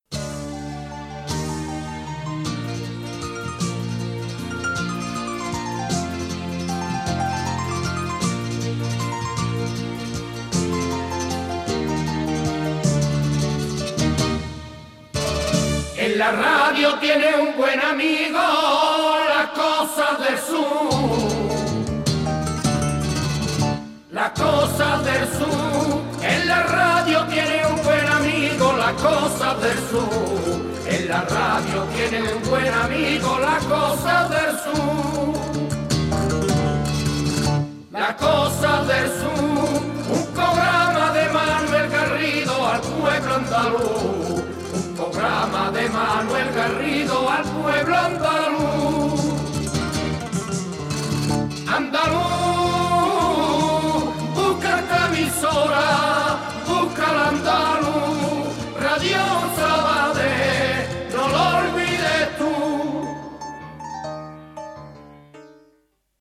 Cançó identificativa del programa